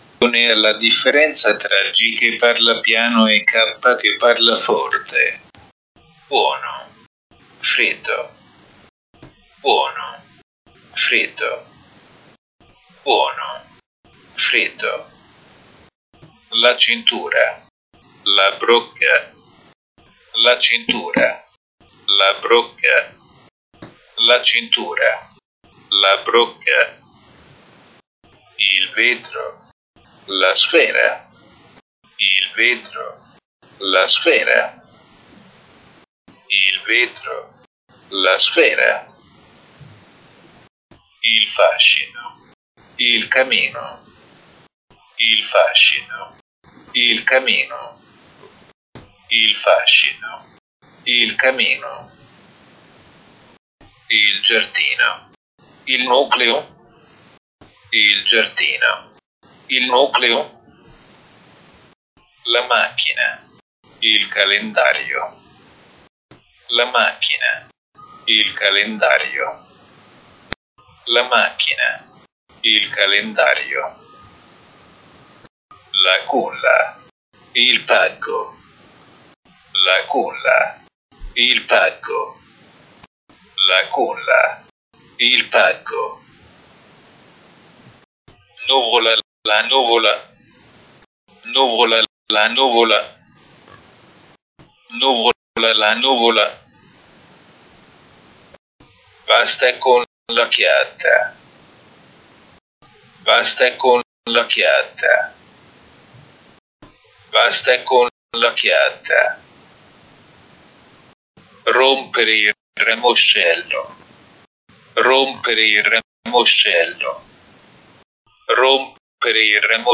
(W73)  –  [IT]  Wörterliste: Anleitung zum Sprechen, „G“ und „K“    /
Elenco di parole: Guida al parlato, “G” e “K”.
„G“ (weich gesprochen) und „K“ (hart gesprochen)
“G” (pronuncia dolce) e ‘K’ (pronuncia dura)